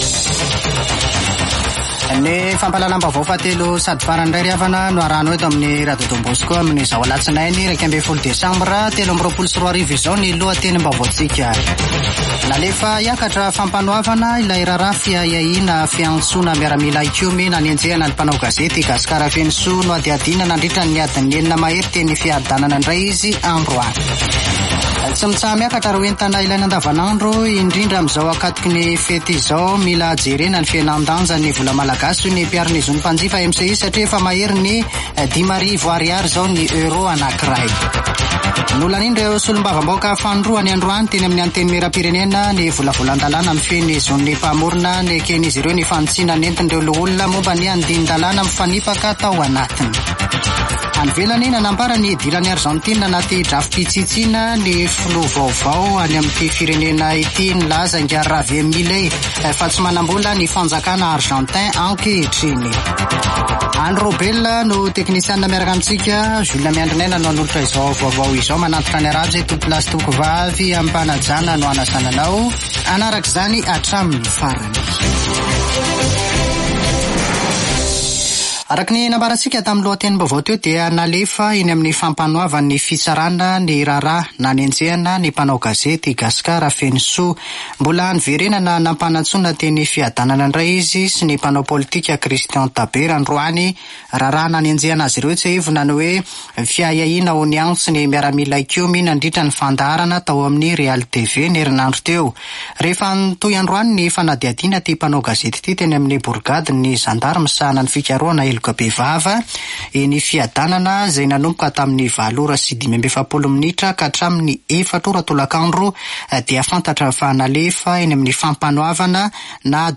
[Vaovao hariva] Alatsinainy 11 desambra 2023